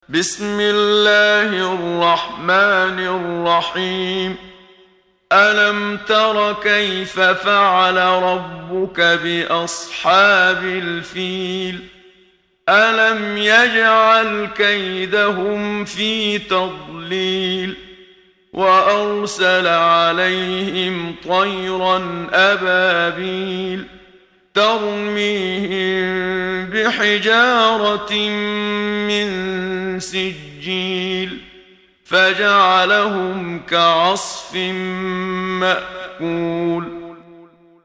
محمد صديق المنشاوي – ترتيل – الصفحة 9 – دعاة خير